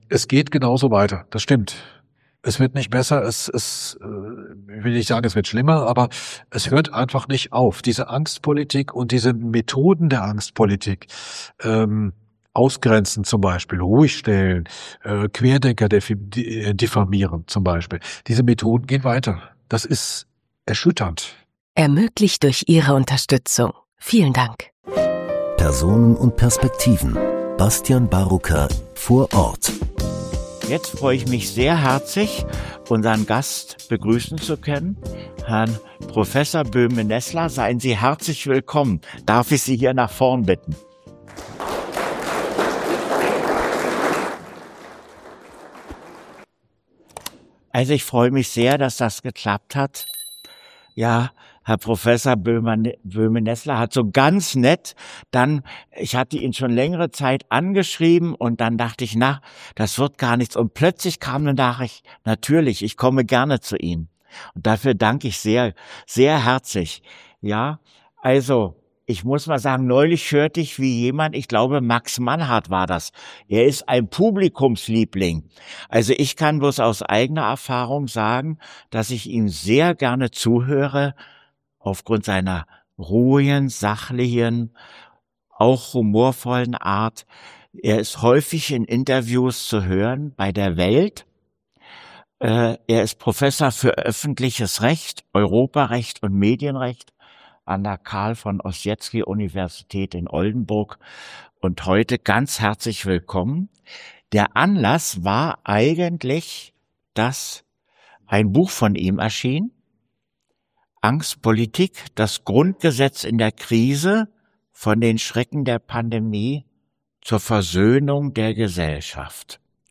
Das Gespräch ist Teil des bereits über Jahre andauernden Malchower Formats, welches immer wieder kritische Stimmen zu Wort kommen läßt.